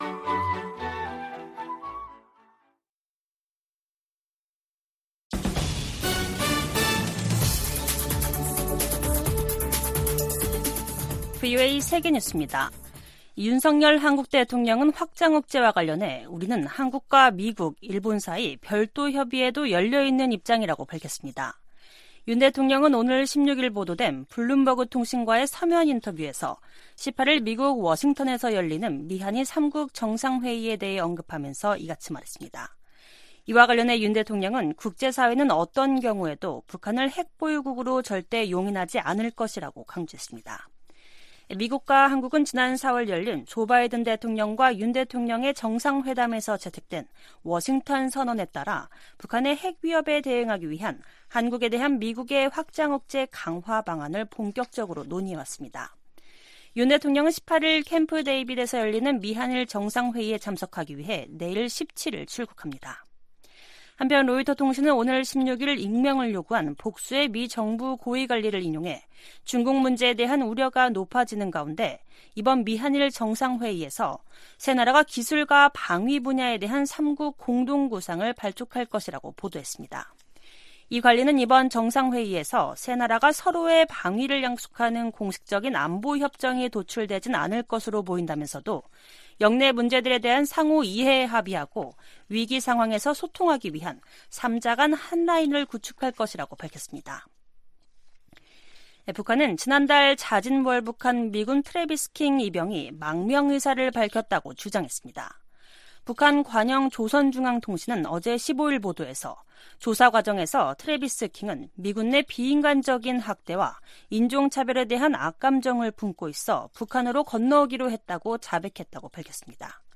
VOA 한국어 간판 뉴스 프로그램 '뉴스 투데이', 2023년 8월 16일 2부 방송입니다. 토니 블링컨 미 국무장관이 오는 미한일 정상회의와 관련해 3국 협력의 중요성을 강조했습니다. 윤석열 한국 대통령은 미한일 정상회의를 앞두고 확장억제와 관련해 미한일 사이 별도의 협의도 열려 있다고 밝혔습니다. 미 국방부는 최근 김정은 북한 국무위원장이 ‘전쟁 준비 태세를 갖추라’고 지시한 것과 관련해 한국, 일본에 대한 미국의 안보 공약은 분명하다고 강조했습니다.